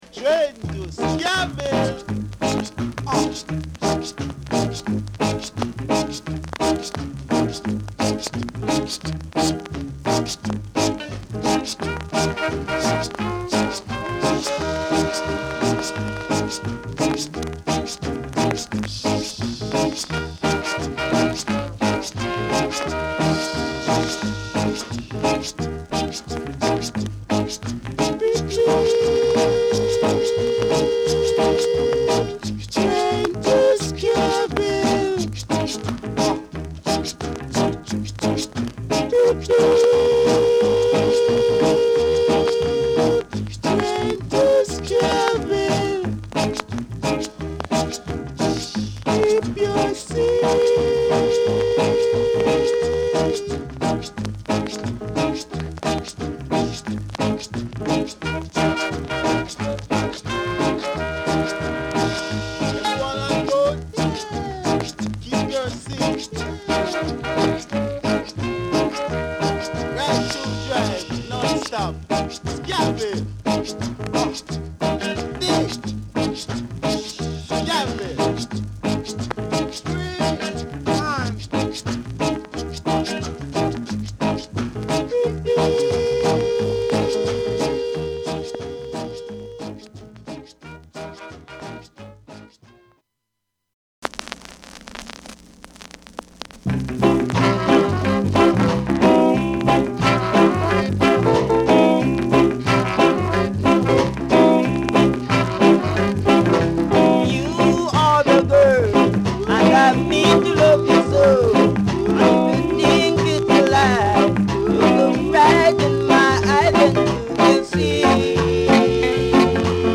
Genre: Rocksteady
グルーヴの抑制が心地よく、ロックステディ期ならではの空気感が滲み出る一枚。